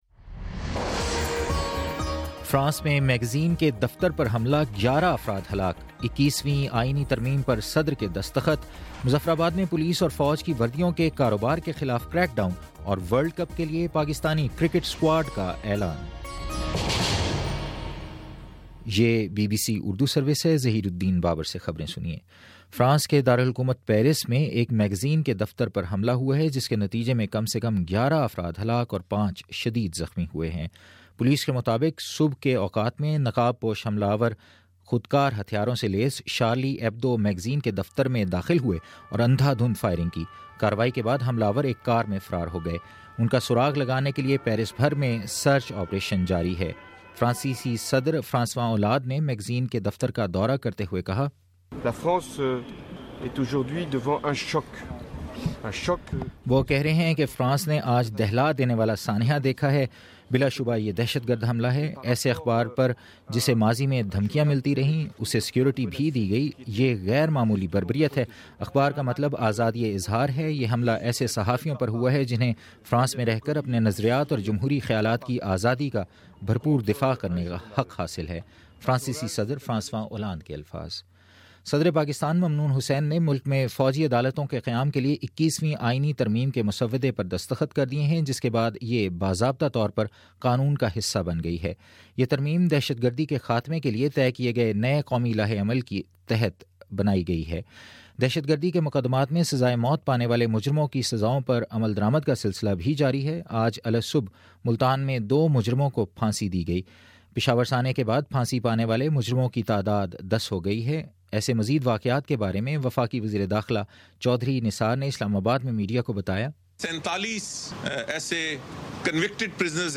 جنوری07 : شام چھ بجے کا نیوز بُلیٹن